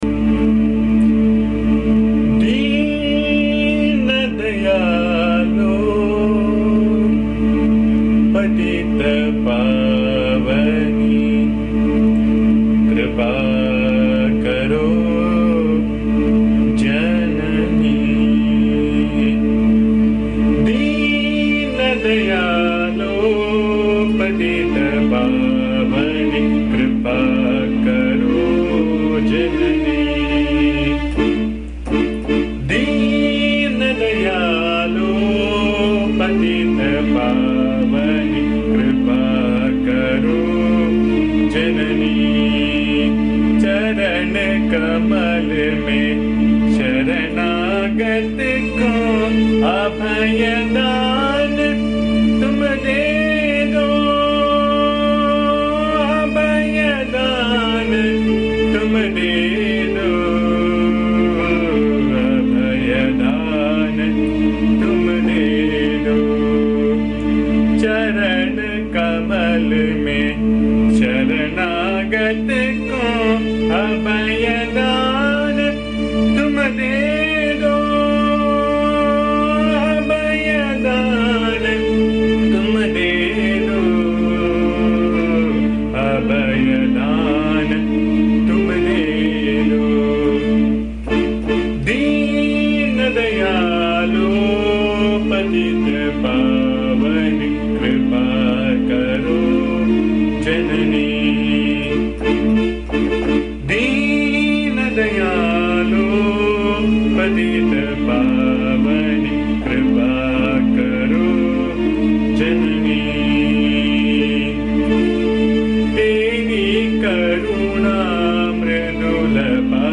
This is a very beautiful song set in Sindhu Bhairavi Raag. This speaks about how a devotee/child prays to the DIVINE MOTHER to seek compassion, protection and adoption so that he is ever immersed in contemplation of the MOTHER.
Please bear the noise, disturbance and awful singing as am not a singer.
AMMA's bhajan song